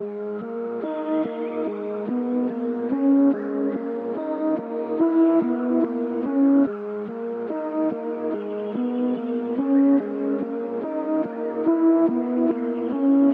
热带雨林 FLUTE
Tag: 144 bpm Trap Loops Flute Loops 2.24 MB wav Key : G